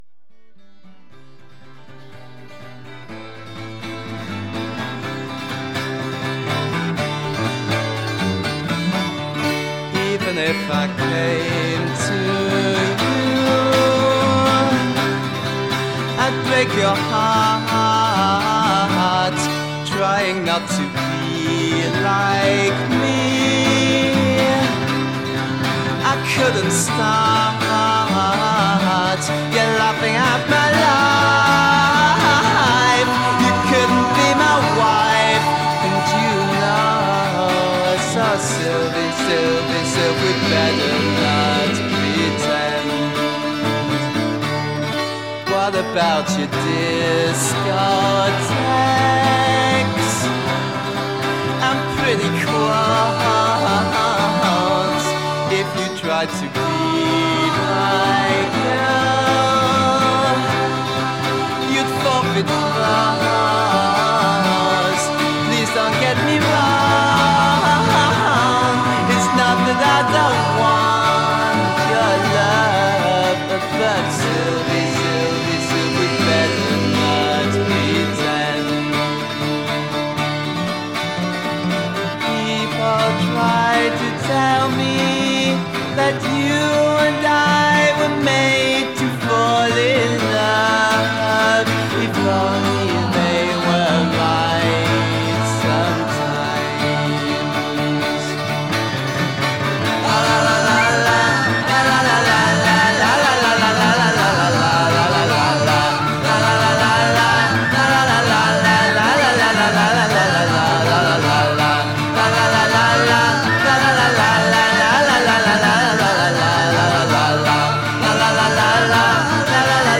Alternative Downtempo Drum & Bass Garage Rock International